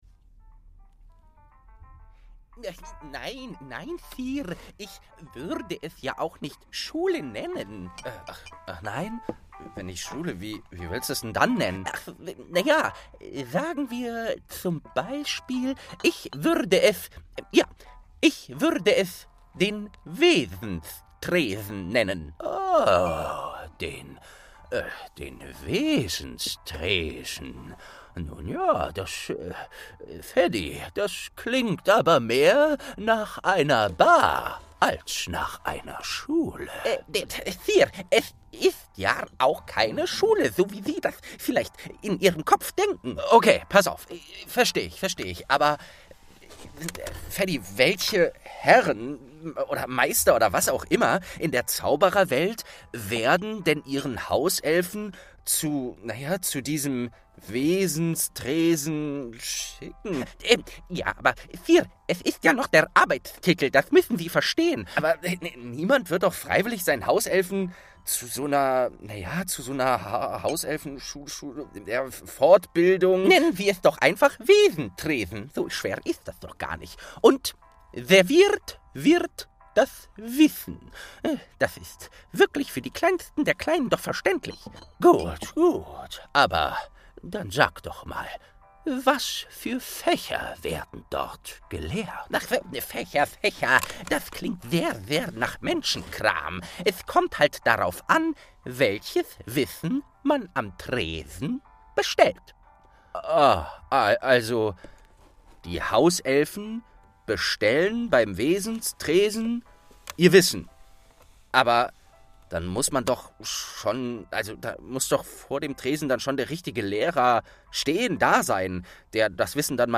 27. Quidditch-Miniaturset| St. 2 ~ Geschichten aus dem Eberkopf - Ein Harry Potter Hörspiel-Podcast Podcast